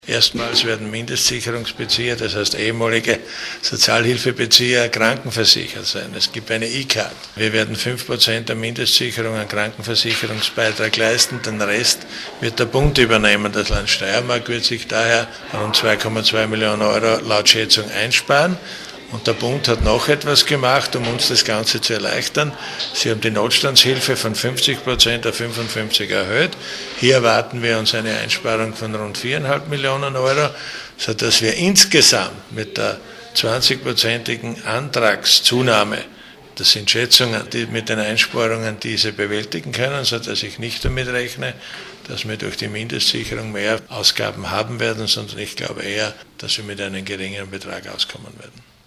Soziallandesrat LH-Stv. Siegfried Schrittwieser:
Statement